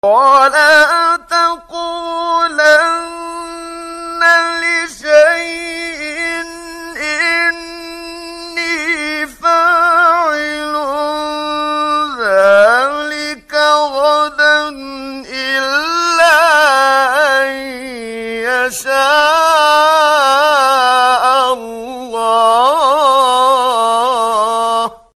قرآن كريم